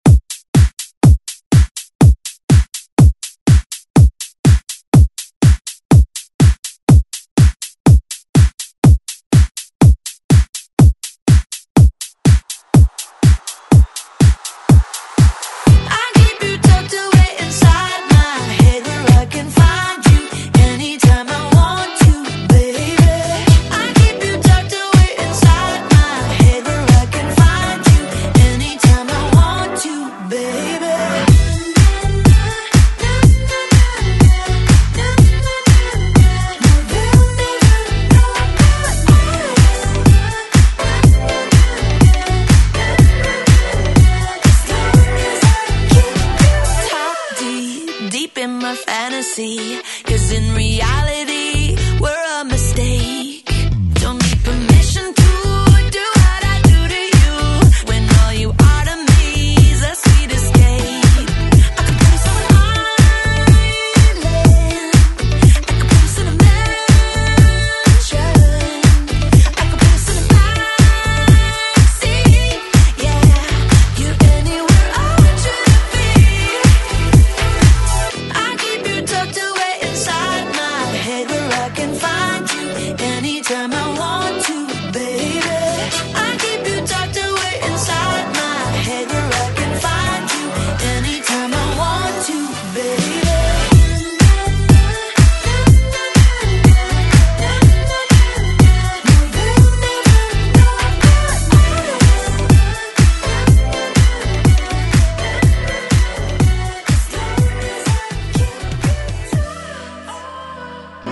Electronic Pop Music
BPM: 123 Time